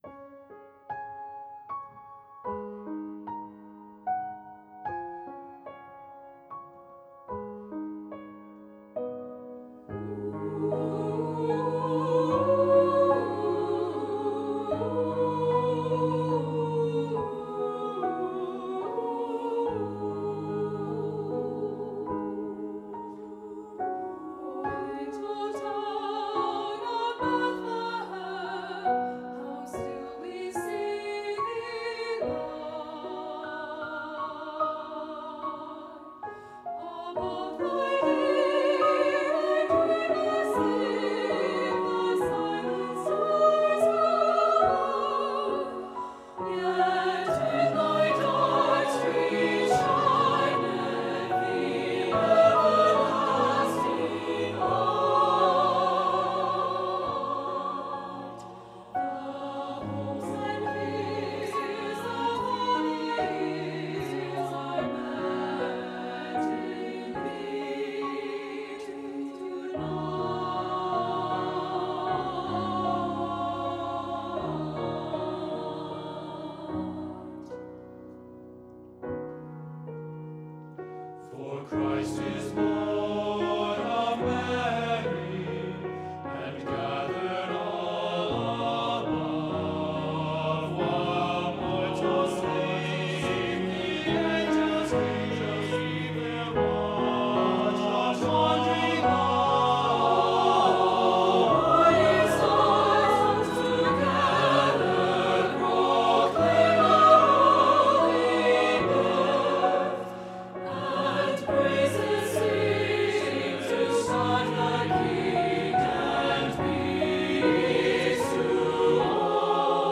SATB with piano